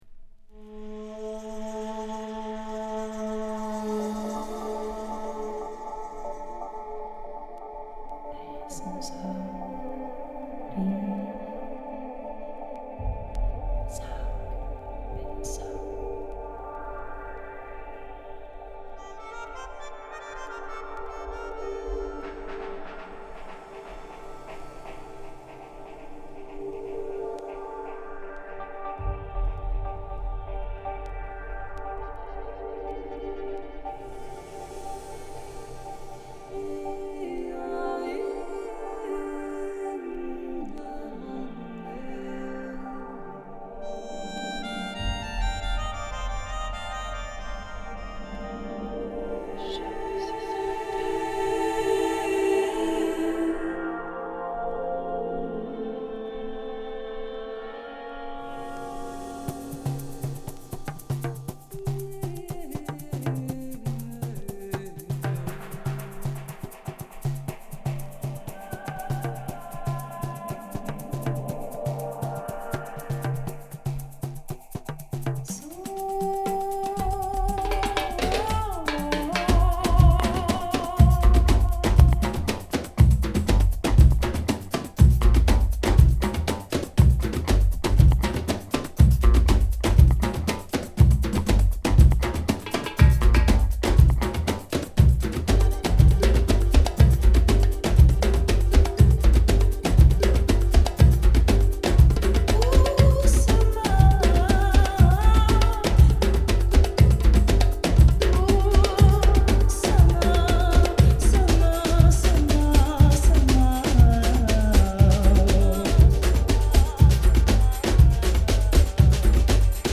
ラテン・ジャズダンス・アルバム！！